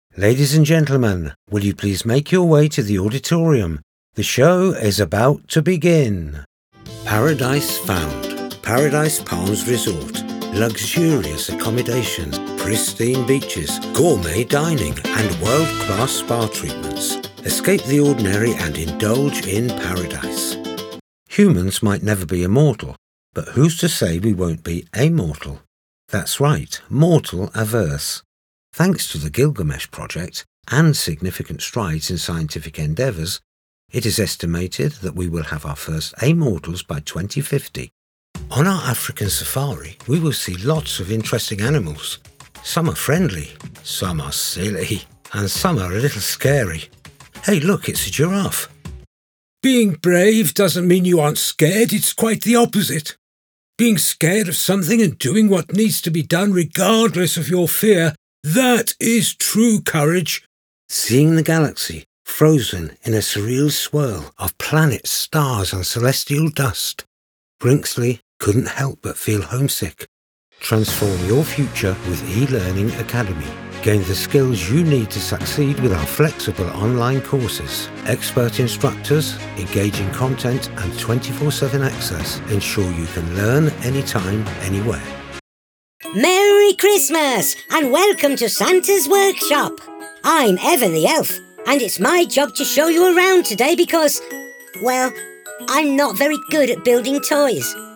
A warm, rich voice that delivers the authority, urgency, epic drama, or down-home feel you're looking for.